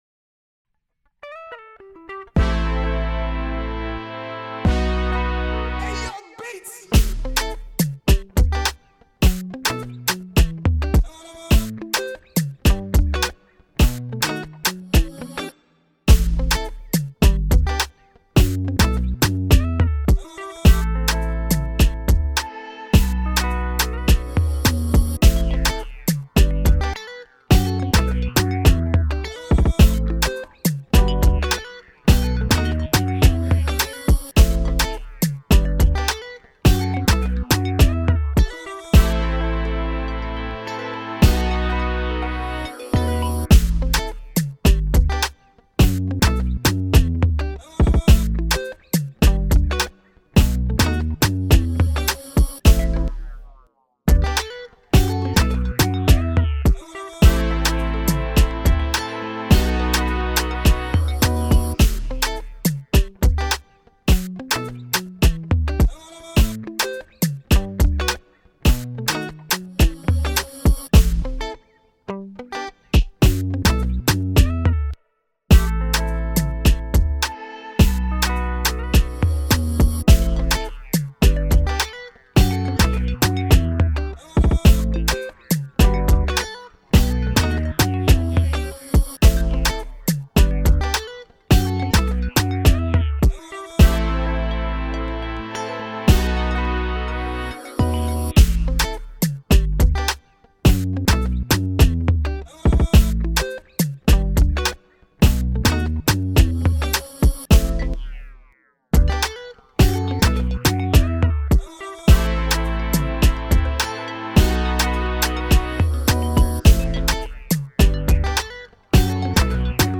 Dancehall/Afrobeats Instrumental